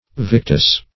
victus - definition of victus - synonyms, pronunciation, spelling from Free Dictionary Search Result for " victus" : The Collaborative International Dictionary of English v.0.48: Victus \Vic"tus\, n. [L.]